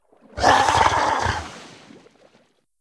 c_seasnake_bat2.wav